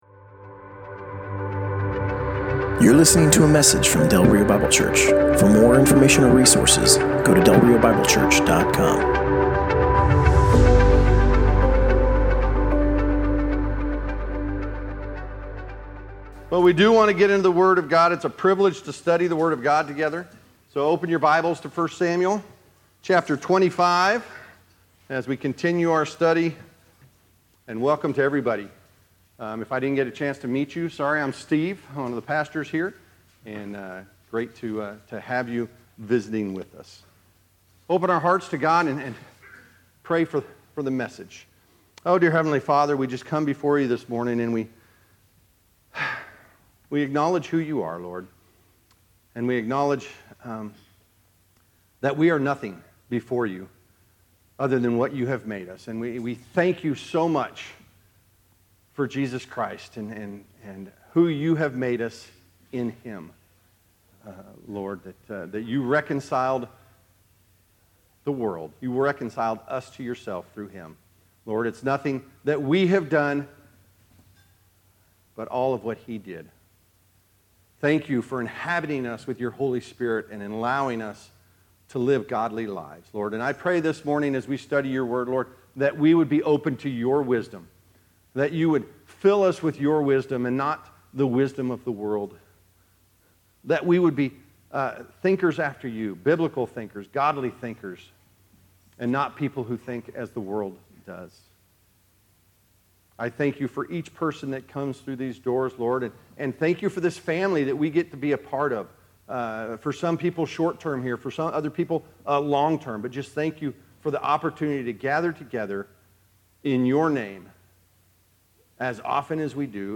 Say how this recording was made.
Passage: 1 Samuel 25: 1-44 Service Type: Sunday Morning